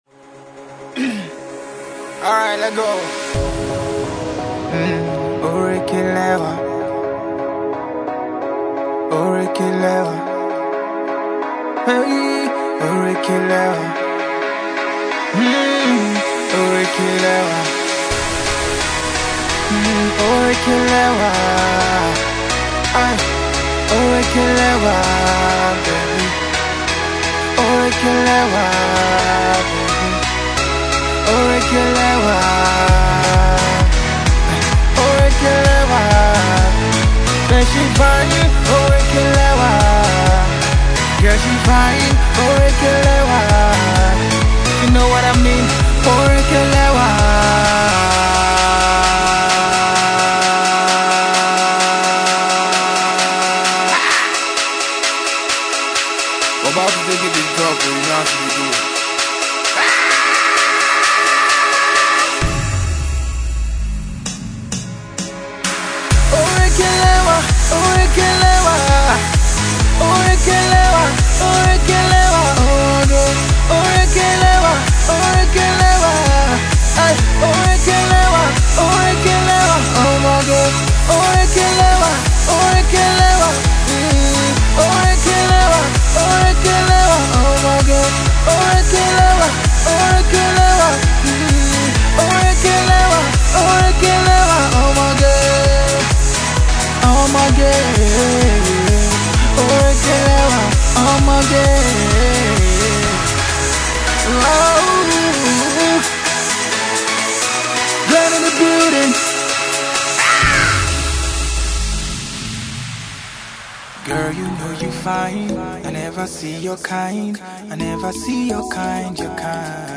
Dance smash